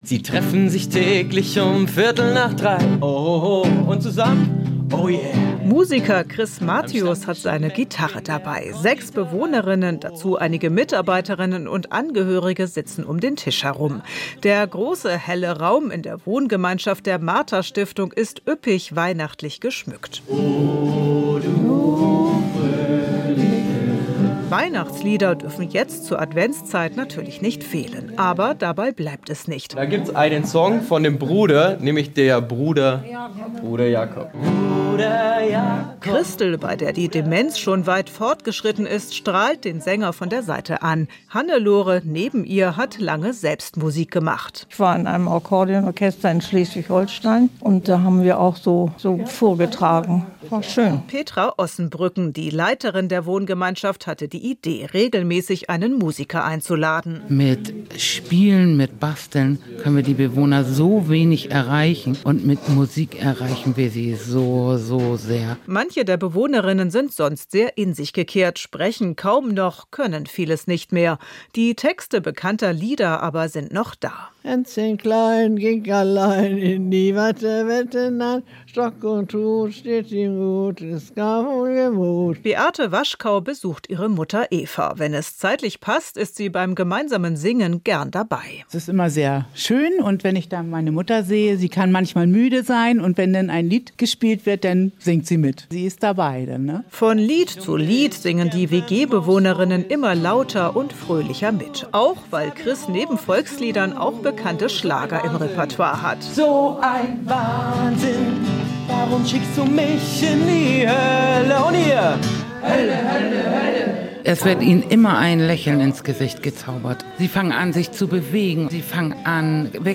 In einer WG für Menschen mit Demenz in Stellingen ist ein Musiker zu Gast. Mit Spenden könnte das Projekt der Martha Stiftung ausgebaut werden.